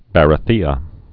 (bărə-thēə)